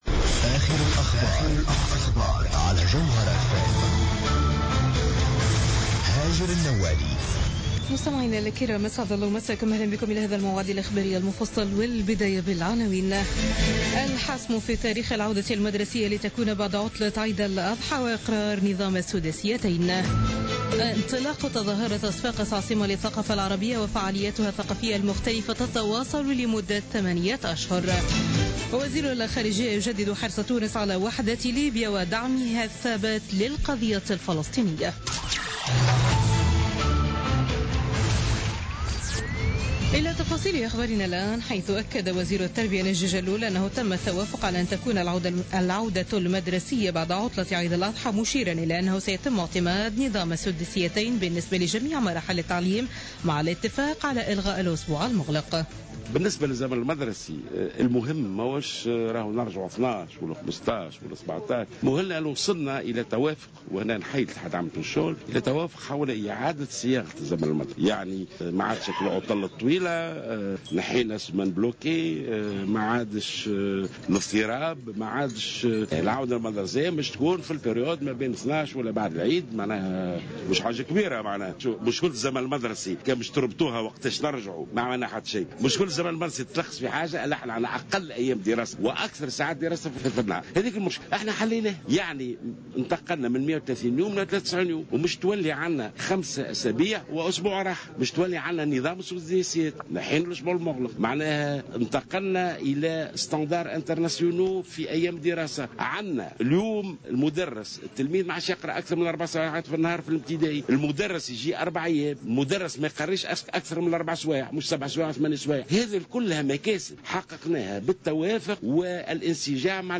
نشرة أخبار منتصف الليل ليوم الأحد 24 جويلية 2016